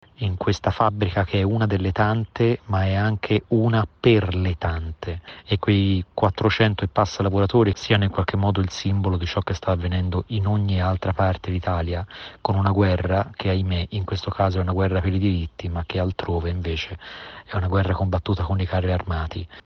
Tra loro anche l’attore/scrittore Stefano Massini: